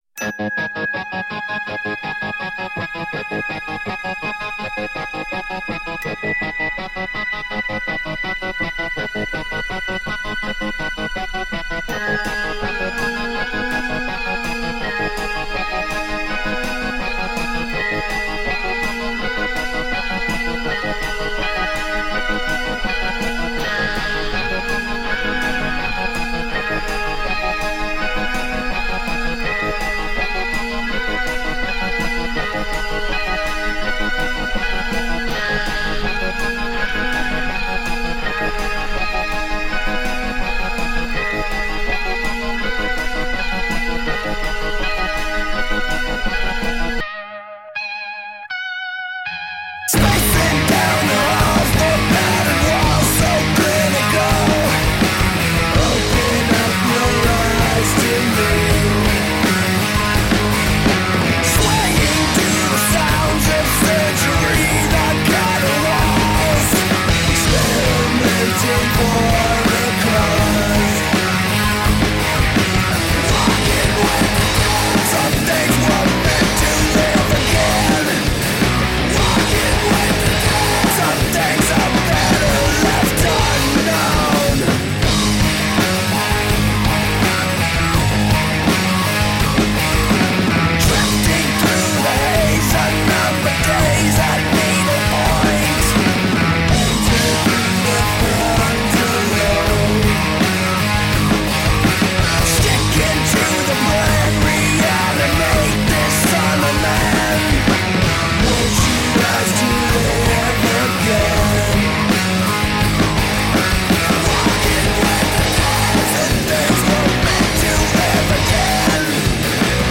heavy rock act
guitar-rock that is as frightening as it is heart pounding
Tagged as: Hard Rock, Metal, Industrial